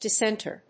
音節dis・sént・er 発音記号・読み方
/‐ṭɚ(米国英語), ‐tə(英国英語)/